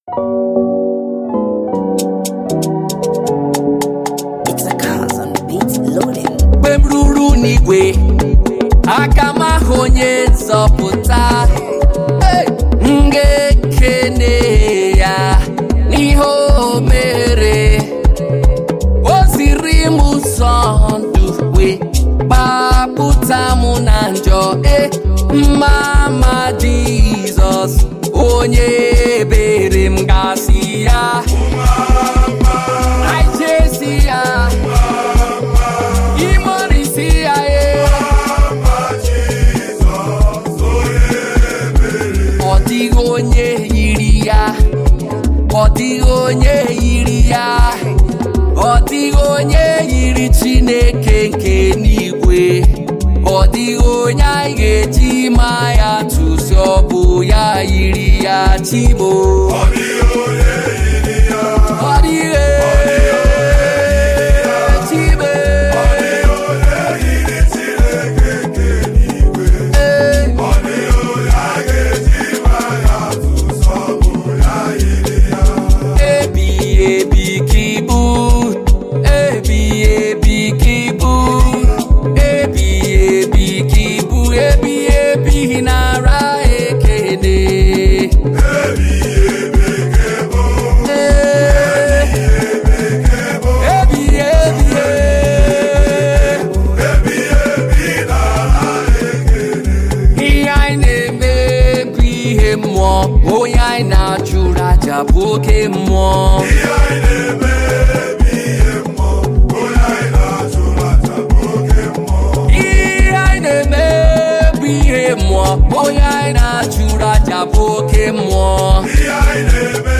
ghetto gospel song